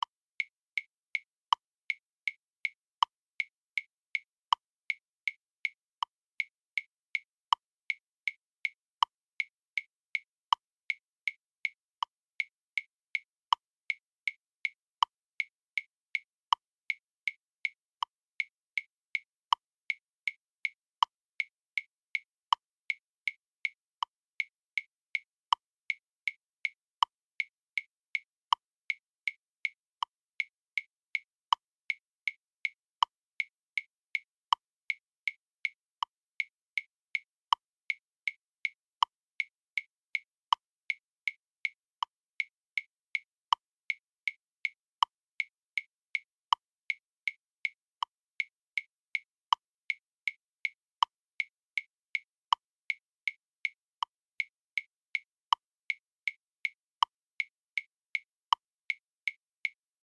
Więc grasz z klikiem tylko pierwszy z 4 dźwięków nastepujących kolejno po sobie i tak samo przy kolejnych 4 dźwiękach.
Kliki metronomu
tempo40szesnastki.mp3